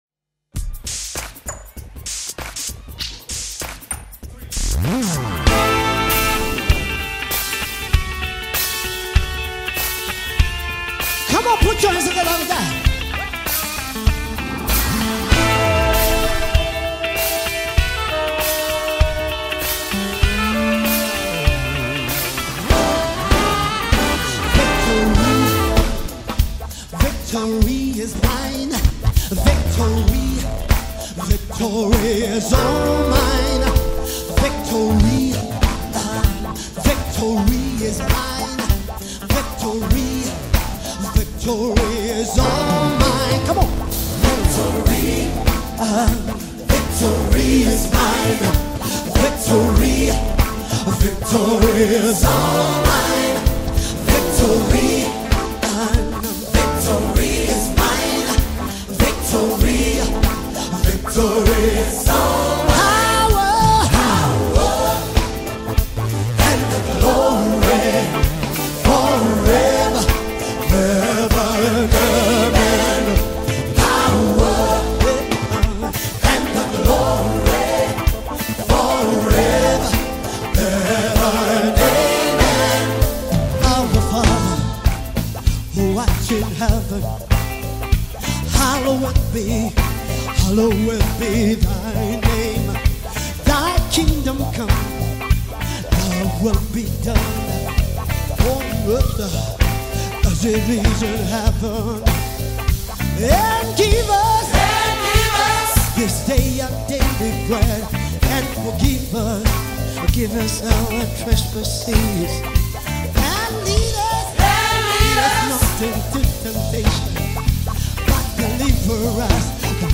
February 24, 2025 Publisher 01 Gospel 0